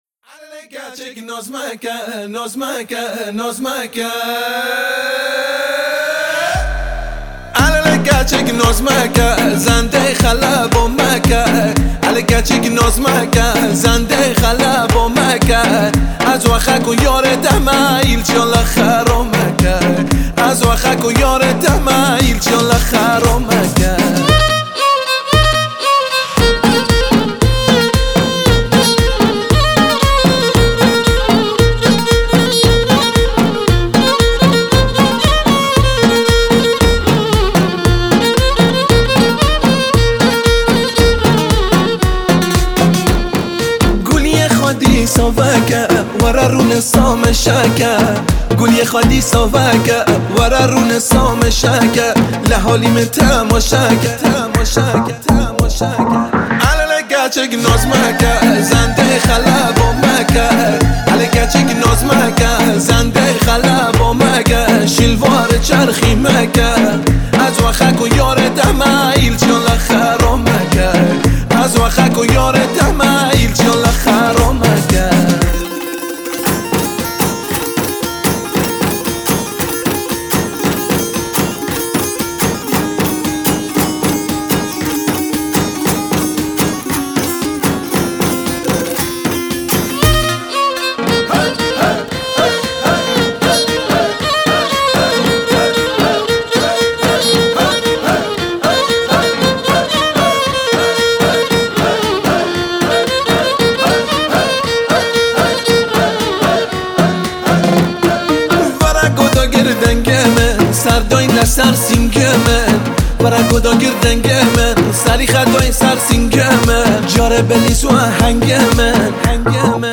آهنگ زیبا و محلیه
کرمانجی